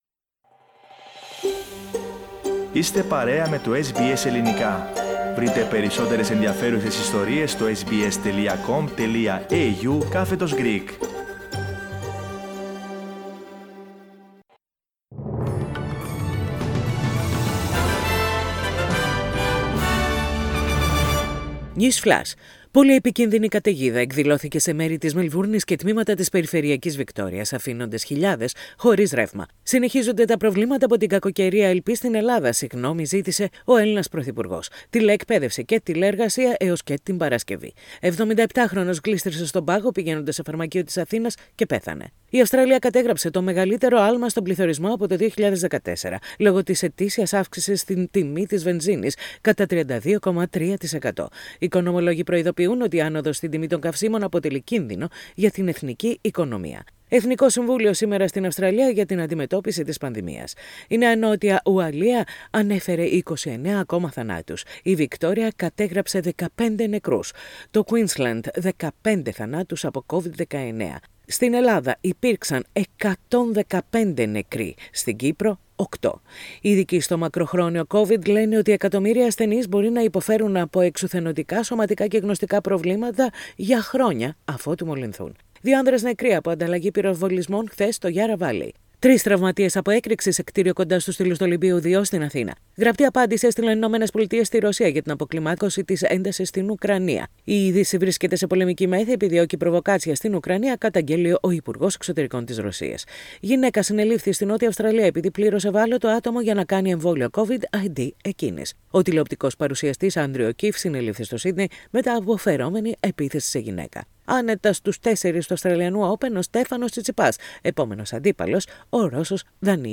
News flash in Greek.